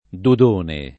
[ dud 1 ne ]